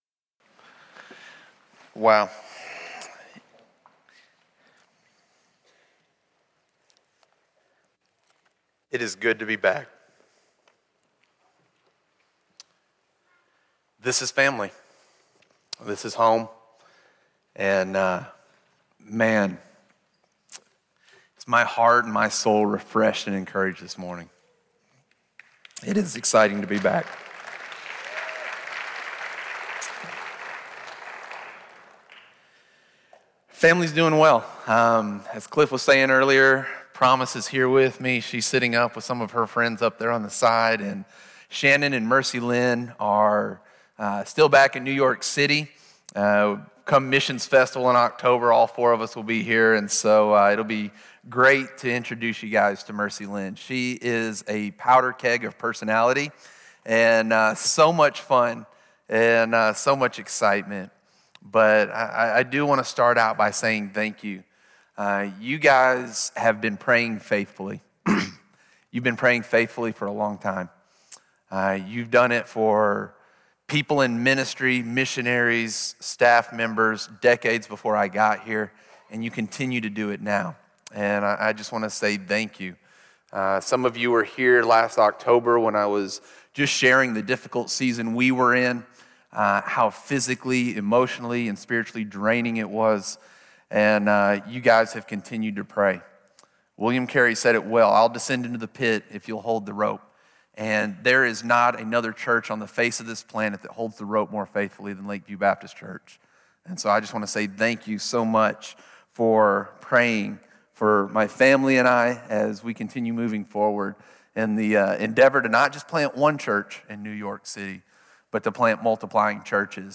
Acts 4:23-31 Service Type: Sunday Morning 1.